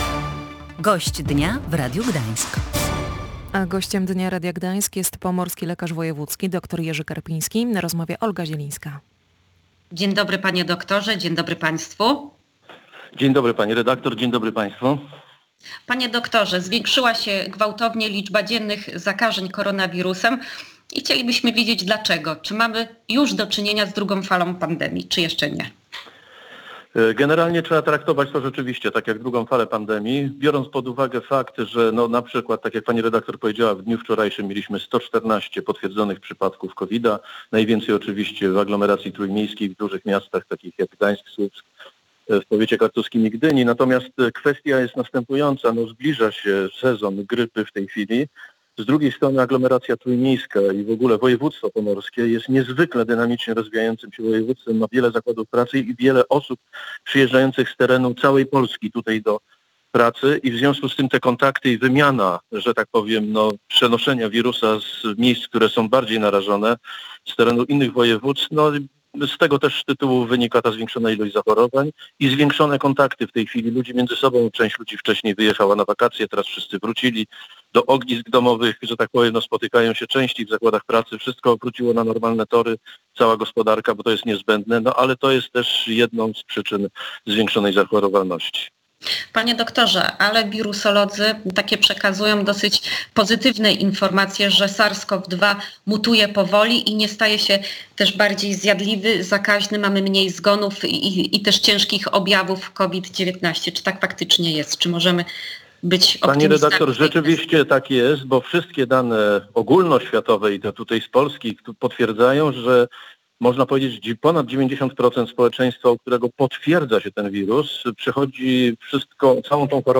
Jak wygląda sytuacja epidemiologiczna na Pomorzu? W jaki sposób realizowana jest strategia walki z koronawirusem? Czy szpitale dysponują wystarczającą liczbą łóżek i respiratorów? Między innymi na te pytania odpowiedział pomorski lekarz wojewódzki dr Jerzy Karpiński w audycji „Gość Dnia Radia Gdańsk”.